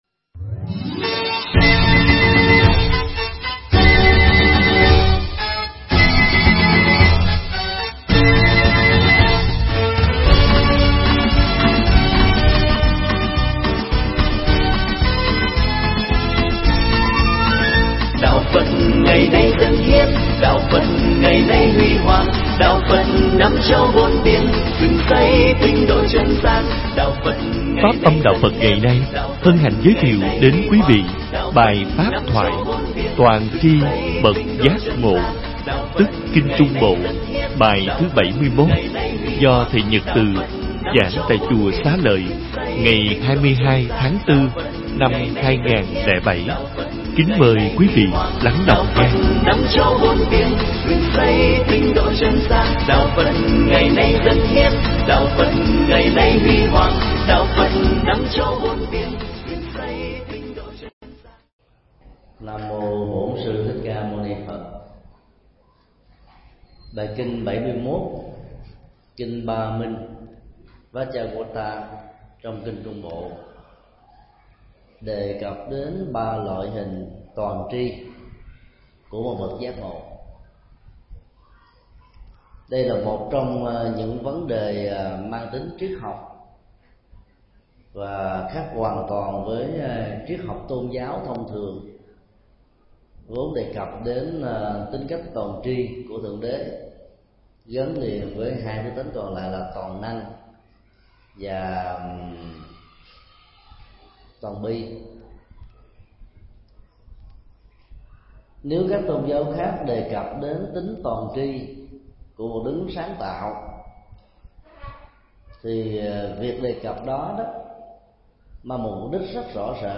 Mp3 Pháp Thoại Kinh trung bộ 71 (Kinh Tam Minh) – Toàn tri của bậc giác ngộ
Giảng tại chùa Xá Lợi ngày 22 tháng 4 năm 2007